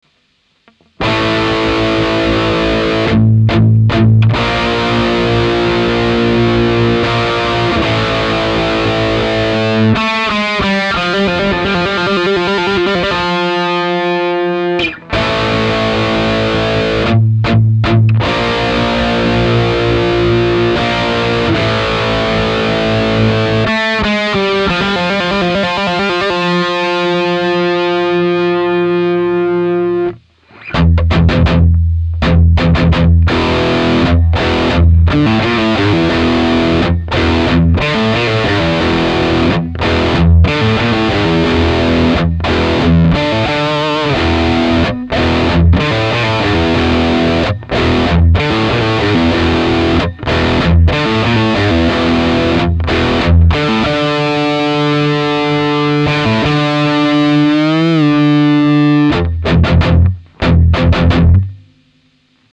Китара -> Lee Jackson GP1000 Tube Preamp ->Ultra-G (Speaker Simulation ON) -> Mixer -> sound card
Ламповият преамп е роден през 80-те години и затова има и такъв звук - няма особено много гейн, но мен страшно си ме кефи и дори намирам звука за плътен.